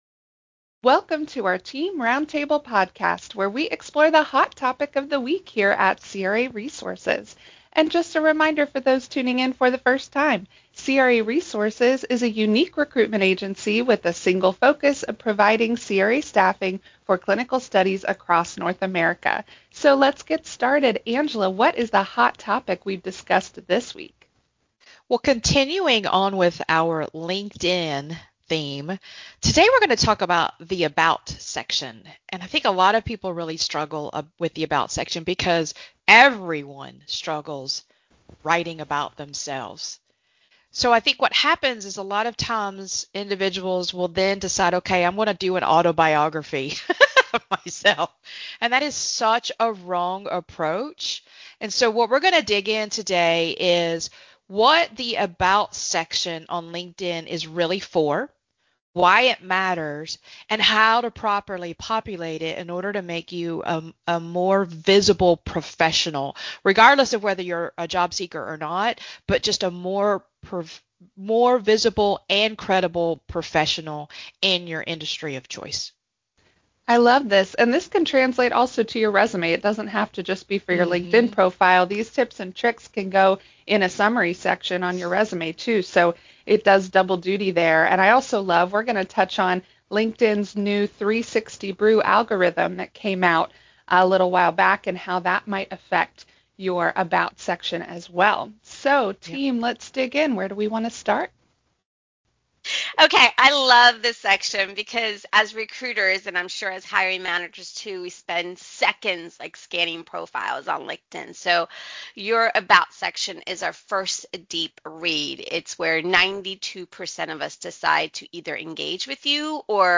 Roundtable: LinkedIn About Section Examples - craresources
In this roundtable episode, our recruiting team pulls back the curtain on what really happens when we open your profile.
You will hear our recruiters walk through real “before and after” LinkedIn About sections.